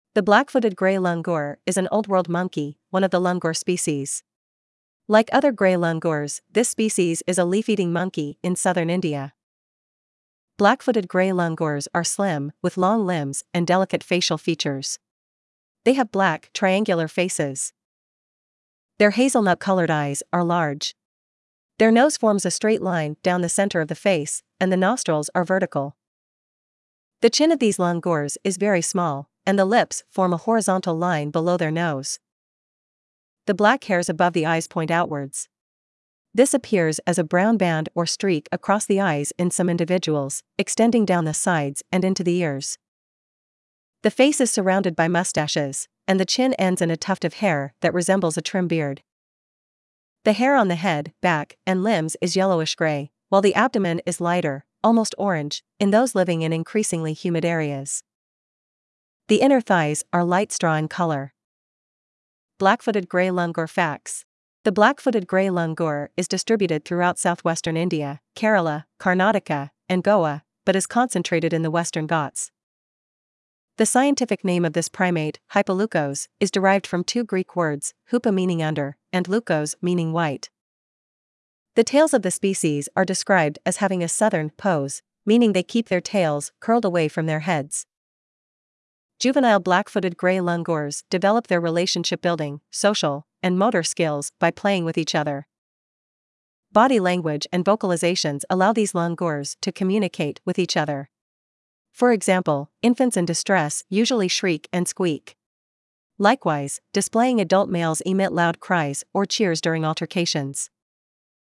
• Body language and vocalizations allow these langurs to communicate with each other. For example, infants in distress usually shriek and squeak. Likewise, displaying adult males emit loud cries or cheers during altercations.
Black-footed-gray-langur.mp3